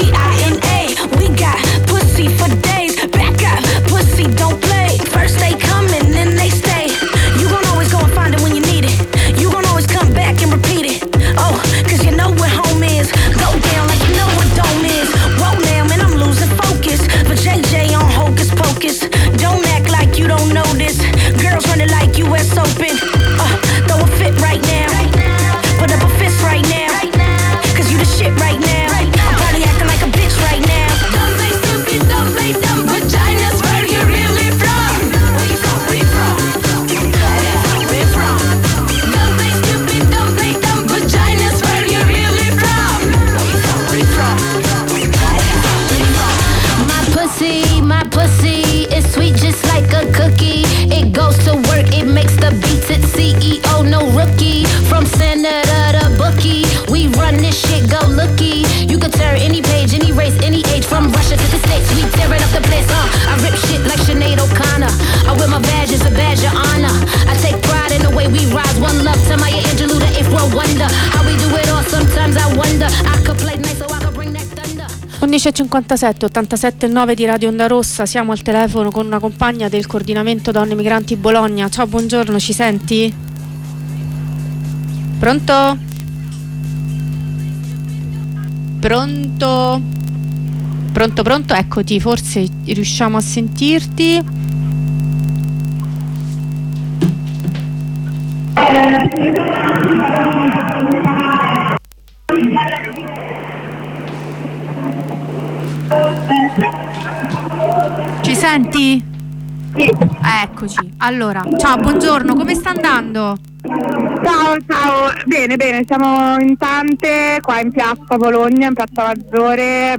Telefonata con studentessa da Milano